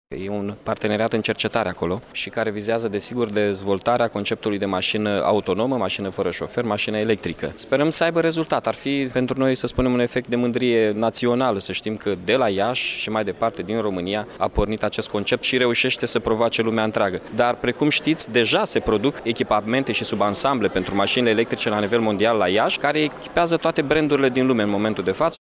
Mihai Chirica a mai spus că între şapte facultăţi din Iaşi, grupul francez Renault şi cel german Continental a fost semnat un parteneriat care vizează dezvoltarea conceptului de maşină fără şofer.